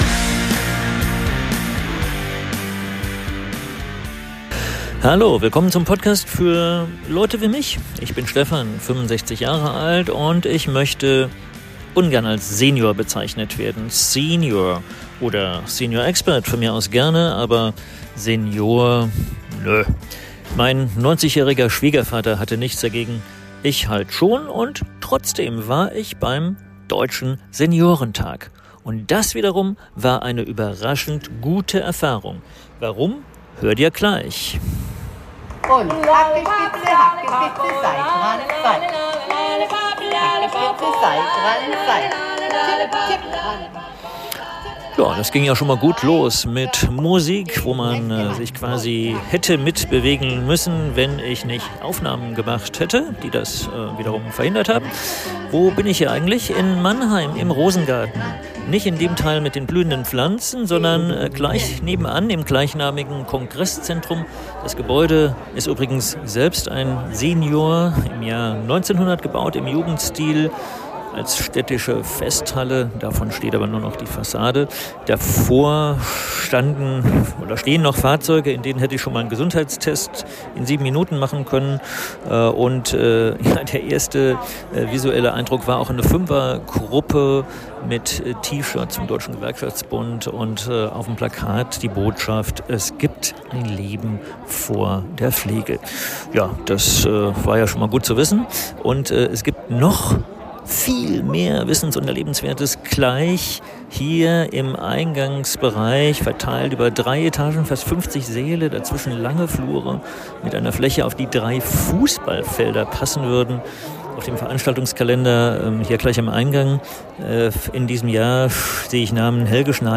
In Mannheim. 180 Aussteller, 120 Messestände, 15.000 Besucher, darunter der Bundeskanzler. In zwei Episoden versuche ich, die Vielfalt des Ereignisses und damit letztlich des Alterns, akustisch einzufangen Mehr